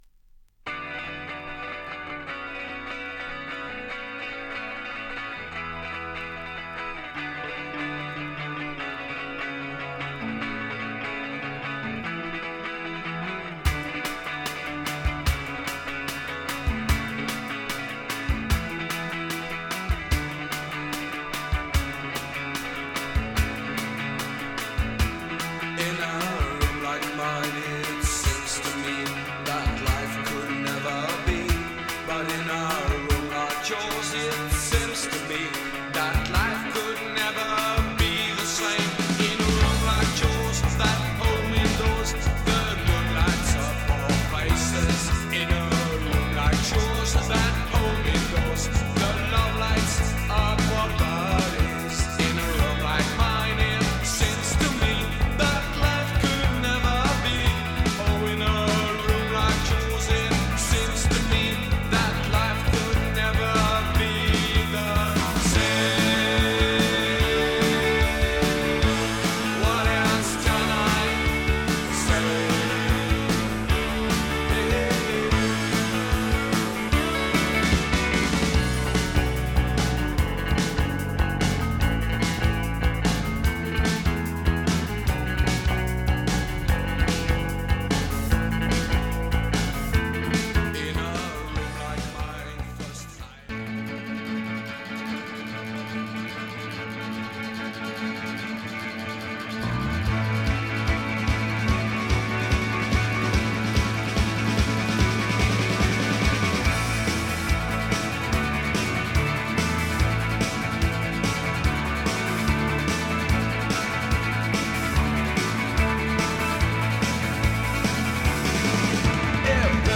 適度なネオサイケ色を感じつつもジャケの通りネオアコ〜インディロック好き直球な１枚。